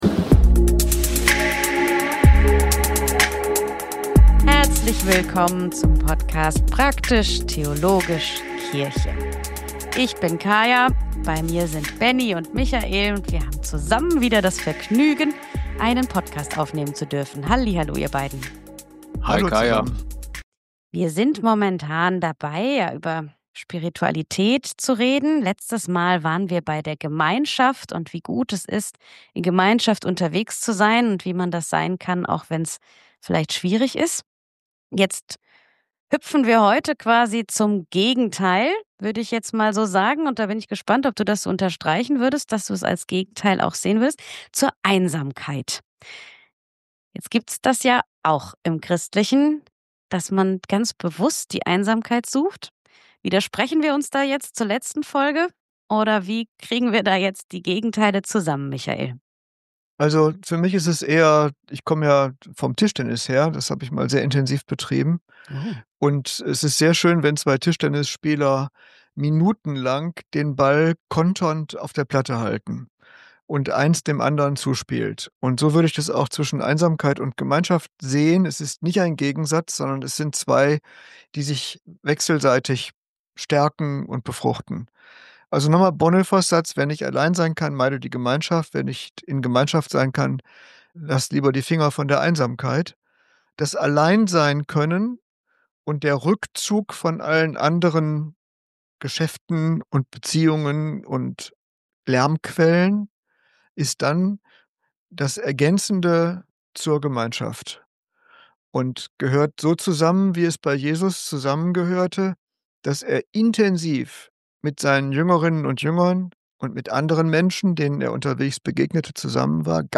- Und warum wir das jetzt wissen: Eine spannende Folge mit lockerer Stimmung!!